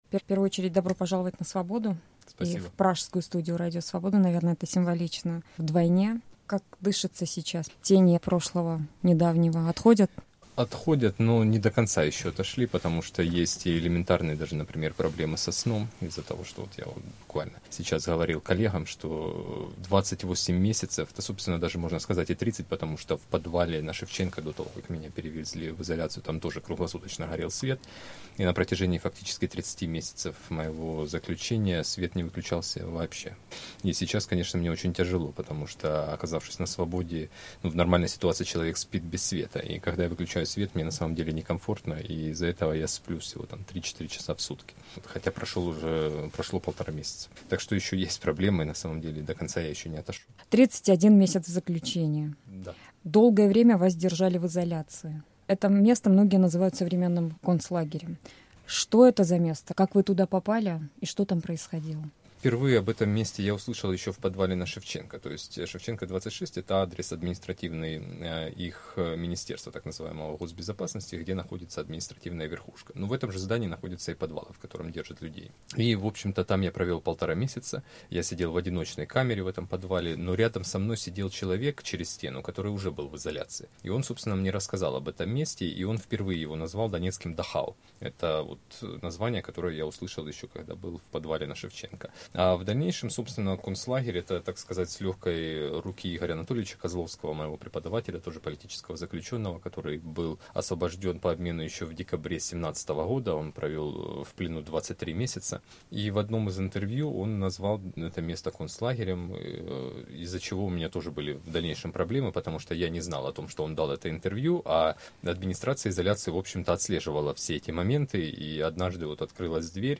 Гость недели – Станислав Асеев